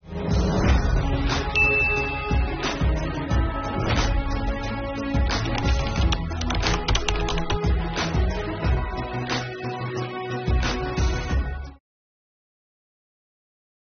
今天（1月26日）上午，国务院新闻办公室举行新闻发布会，国家税务总局副局长王道树介绍减税降费促发展强信心有关情况，并答记者问。